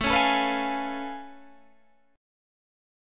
Listen to A+M9 strummed